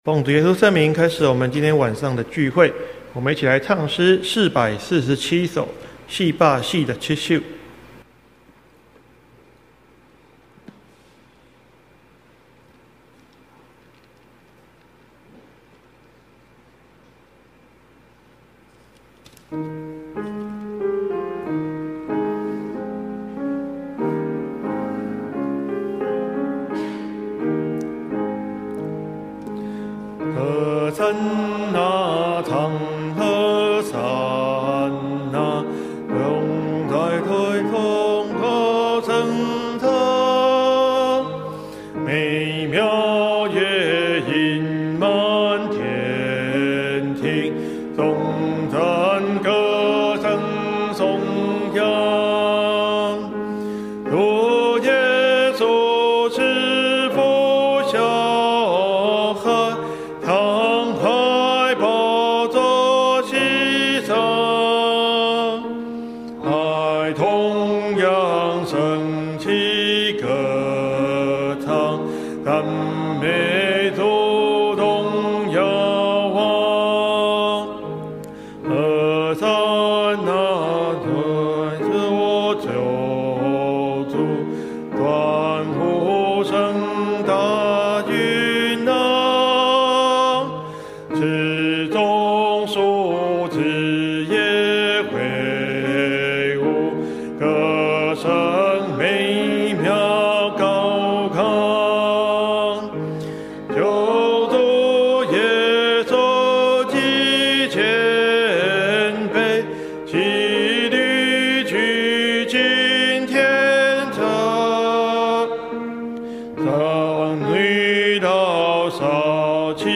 (因設備故障,中間有中斷,詳可參考講義)